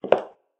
inside-step-1.ogg.mp3